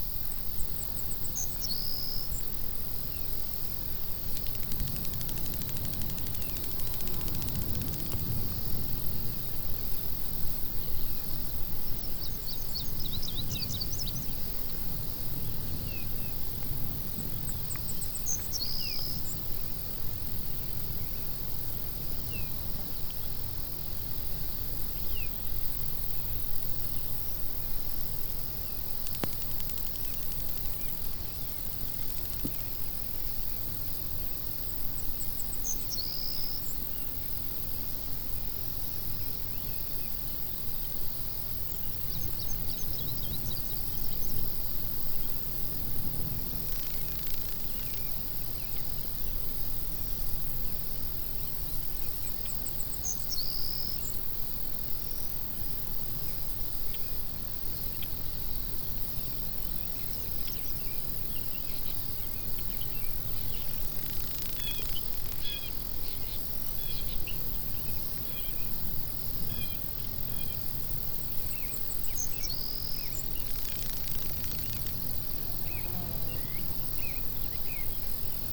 tenkoku_dayambient.wav